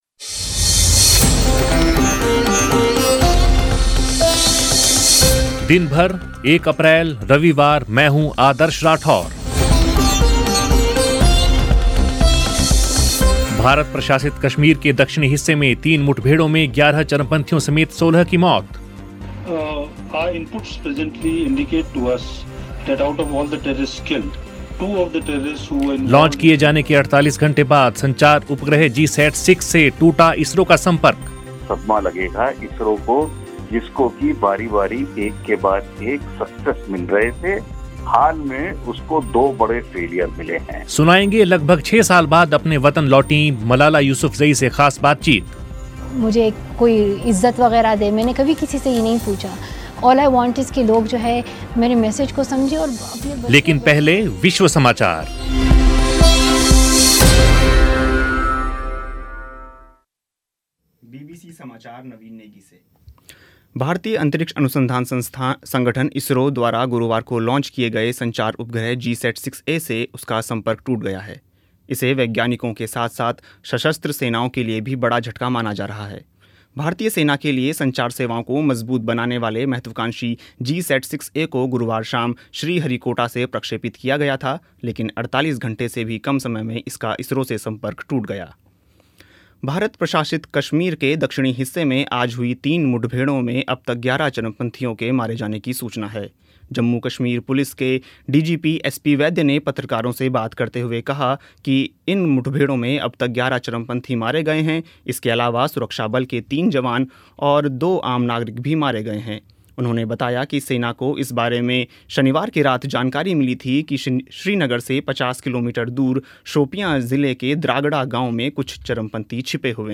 सुनिए, लगभग छह साल बाद अपने वतन पाकिस्तान लौटीं मलाला यूसुफ़ज़ई की बीबीसी से ख़ास बातचीत